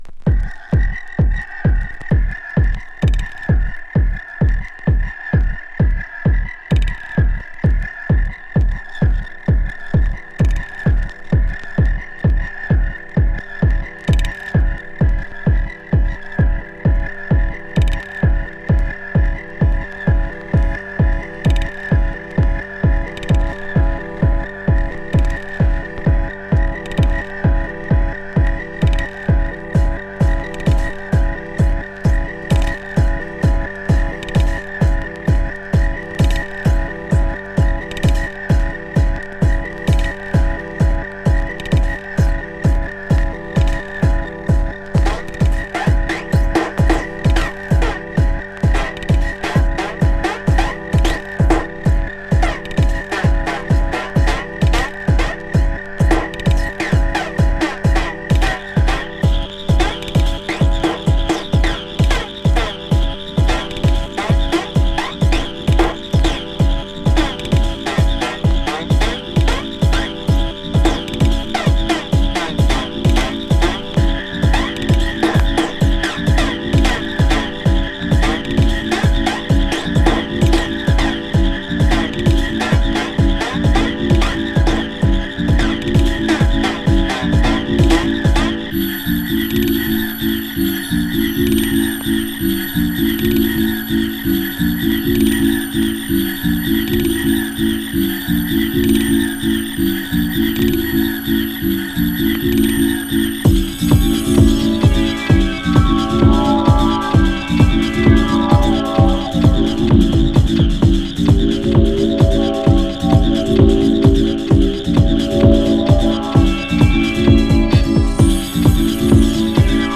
傑作エキスペリメンタル・テクノ!